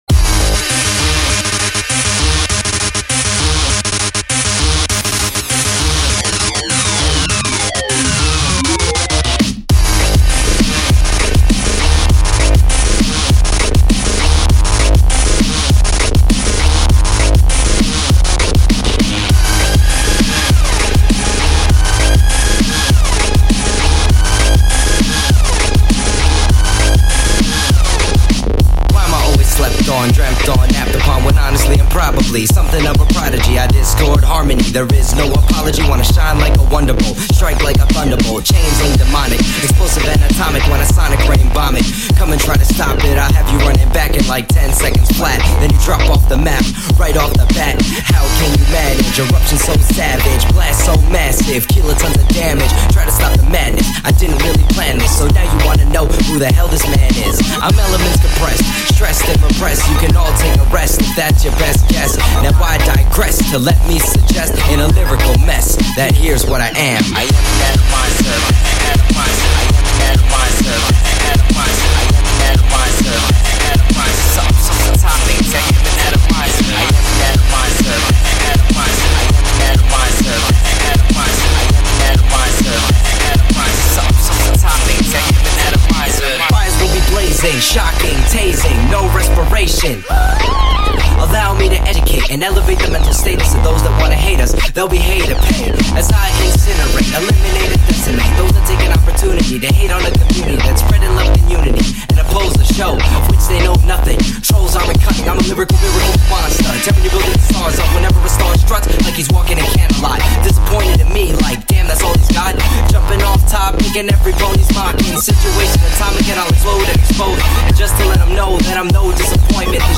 Rapping